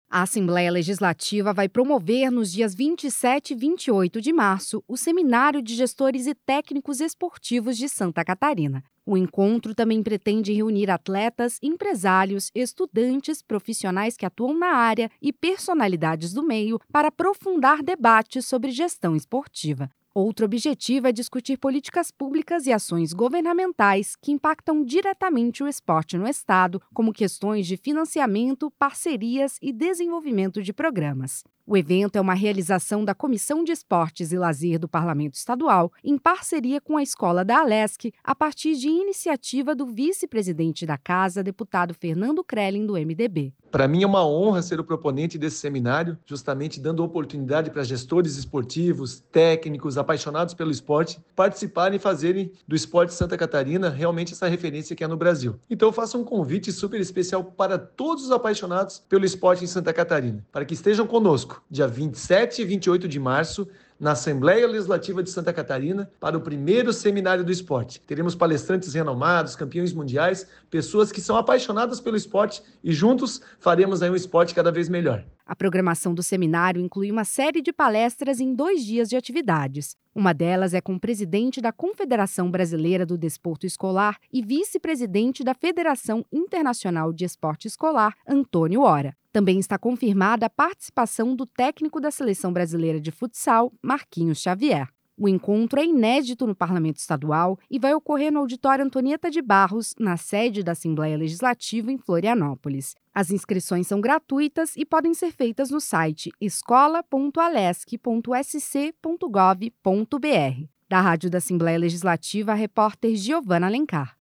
Entrevista com:
- deputado Fernando Krelling, vice-presidente da Alesc e proponente do evento.